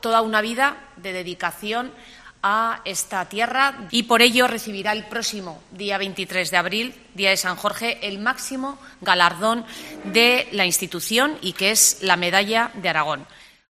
La portavoz del Gobierno, Mar Vaquero, anuncia la Medalla de Aragón para Javier Lambán